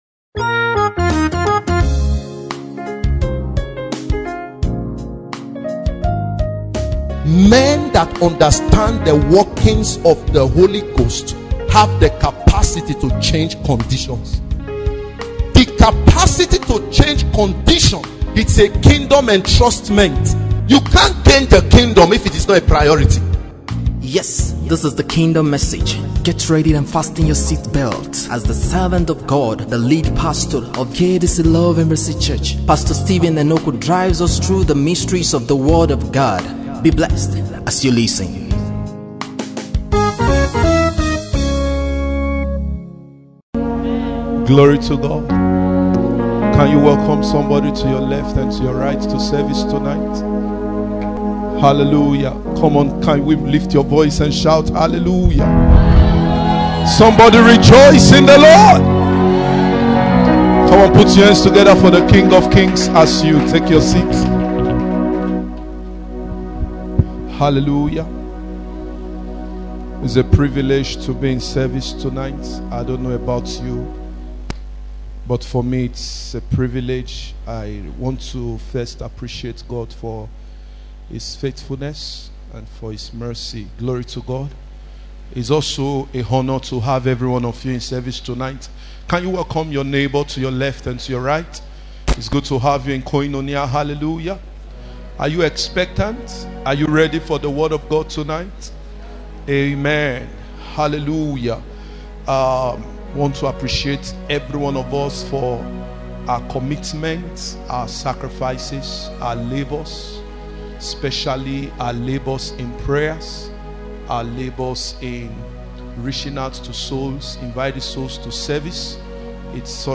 Renewing Your Mind From The Fear And Consciousness of Lack Play Download Renewing Your Mind From The Fear And Consciousness of Lack Pt. 4 Preacher